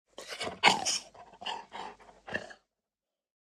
Minecraft Version Minecraft Version latest Latest Release | Latest Snapshot latest / assets / minecraft / sounds / mob / wolf / big / panting.ogg Compare With Compare With Latest Release | Latest Snapshot
panting.ogg